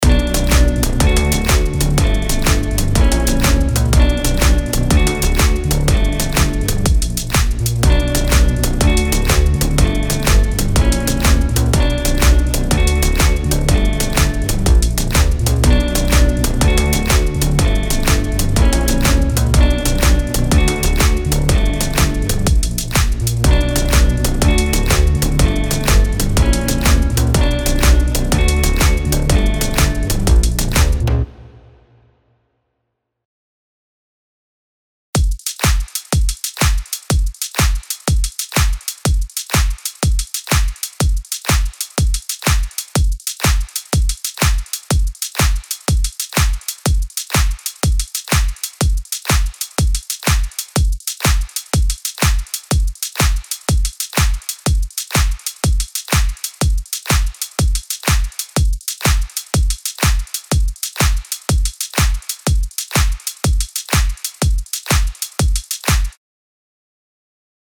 LP 102 – LOOP – FUTURE HOUSE – 123BPM Bm